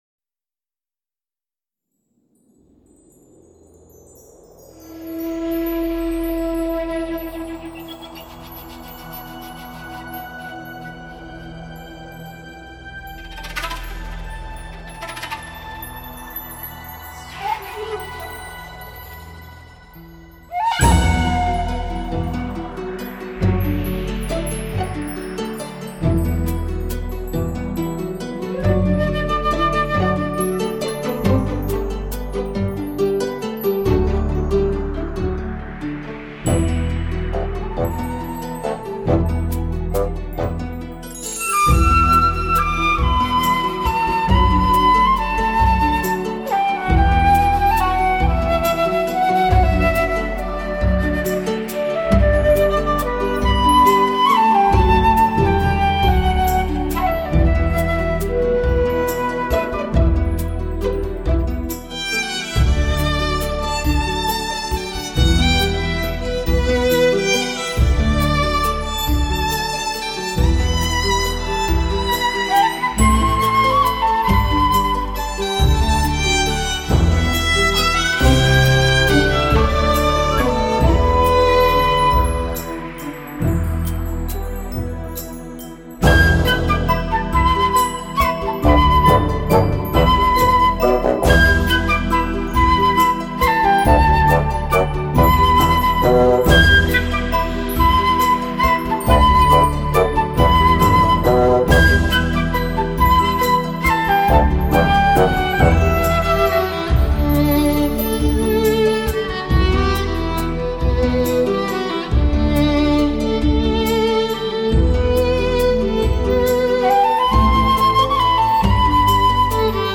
以更具透明度的音质，配合浪潮般律动的编曲，呈现出抚慰心灵的听觉效果